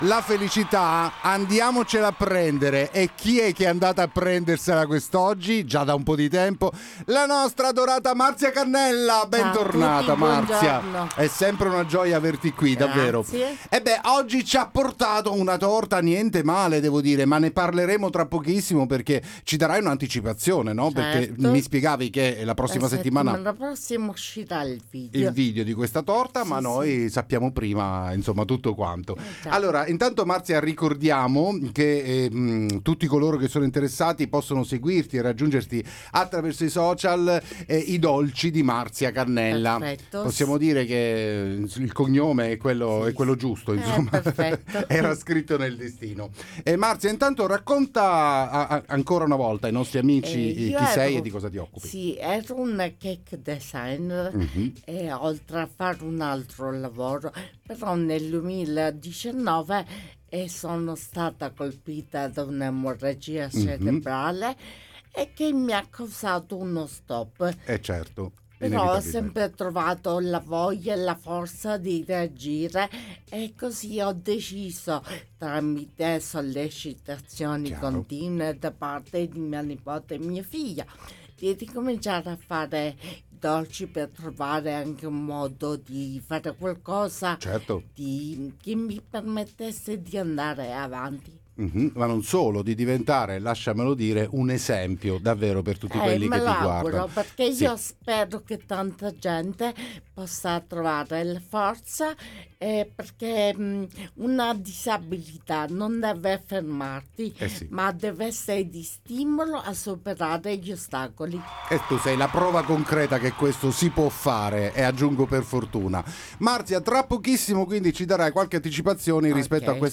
Buongiorno da Marino Interviste